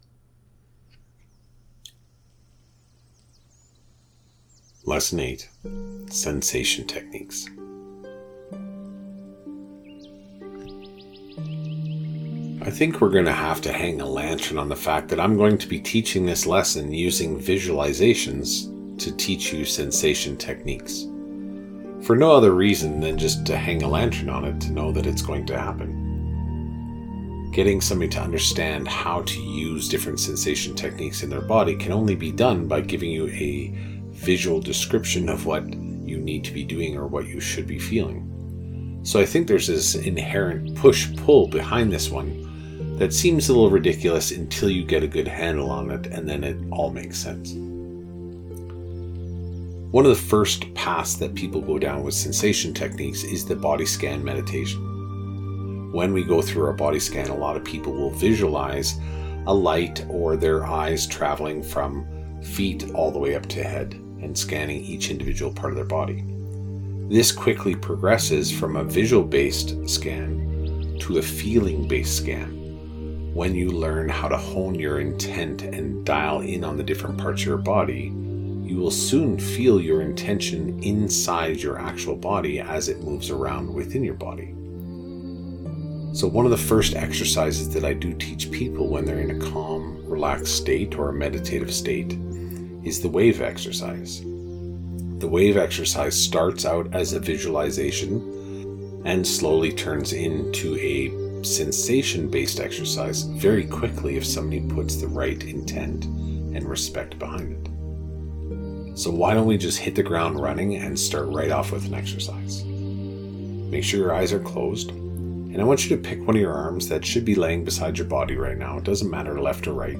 Meditation-Lesson-8.mp3